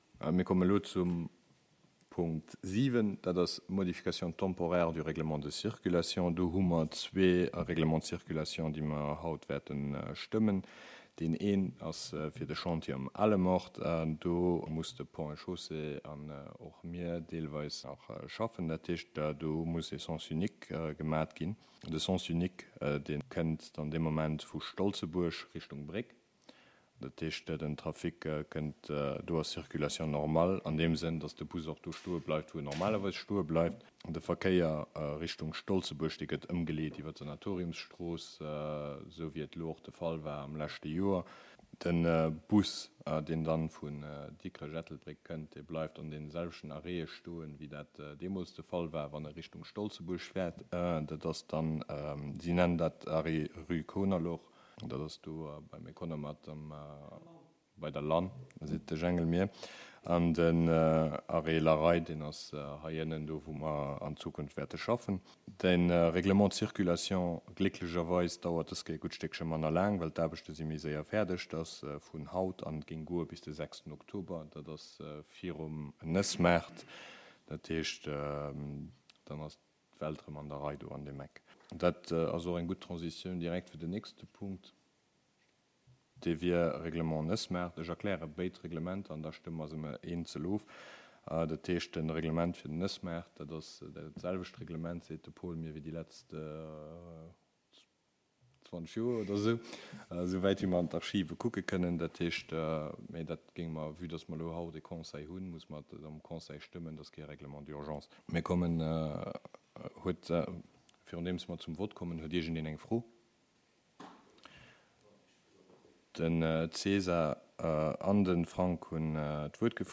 Conseil Communal  du mercredi 28 septembre 2022 à 19h00 heures Centre Culturel Larei en la salle Bessling